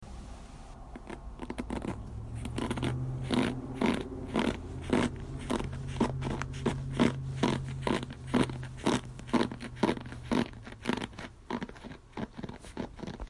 Brush Concrete
描述：point park
标签： brush concrete
声道立体声